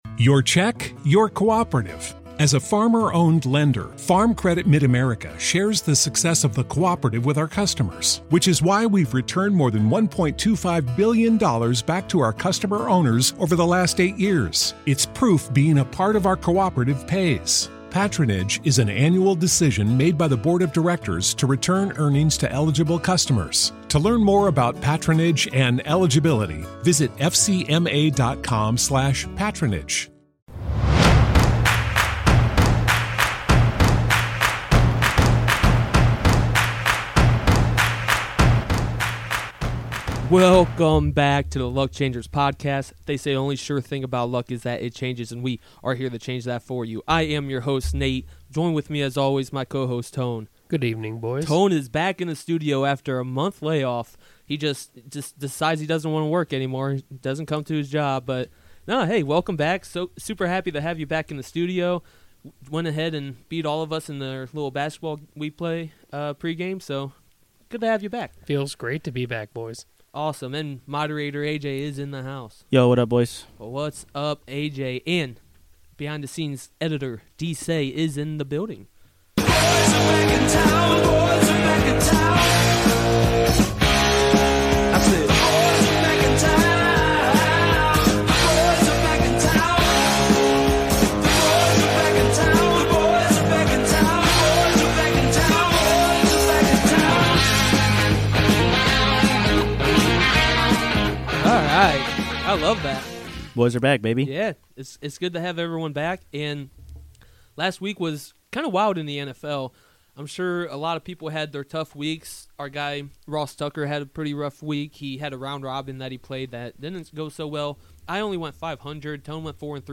Everyone is back in the studio as we recap week 4 and give our takes on NFL Week 5. As always we are brining you are Draft Kings line ups as well!!!